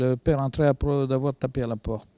Langue Maraîchin